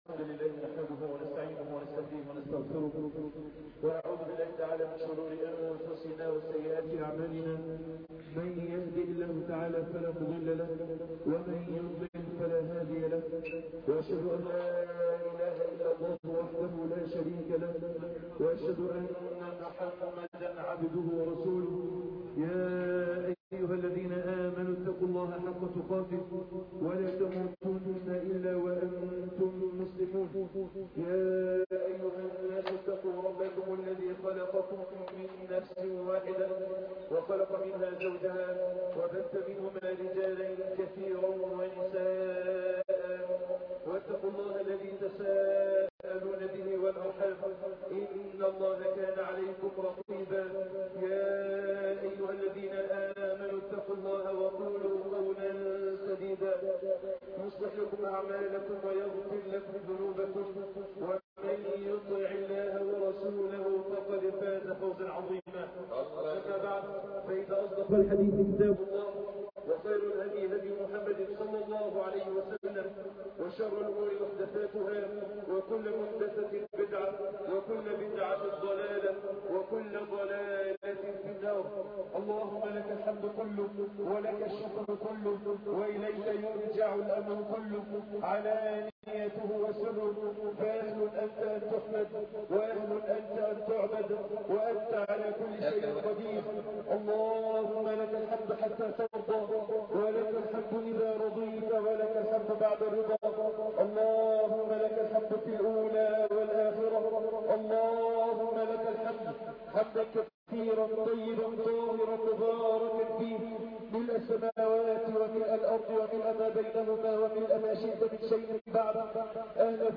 عرفات مهبط الرحمات _ خطبة عرفة ذو الحجة 2015